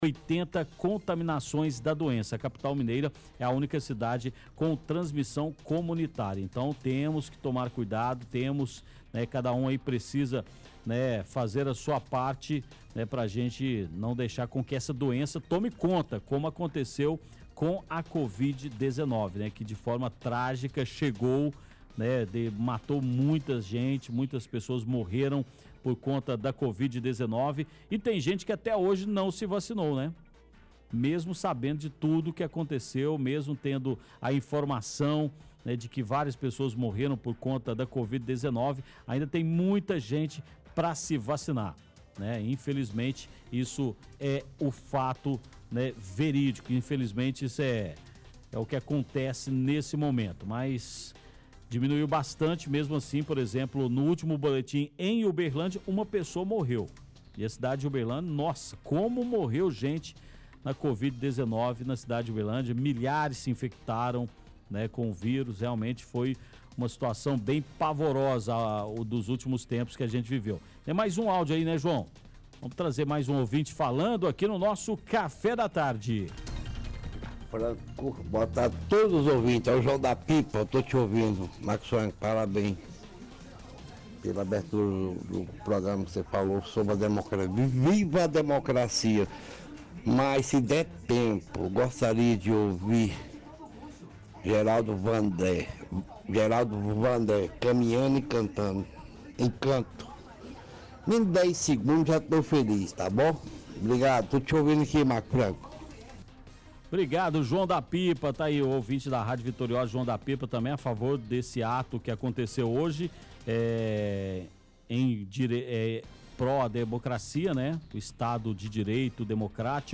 Covid-19 – Comentário do Apresentador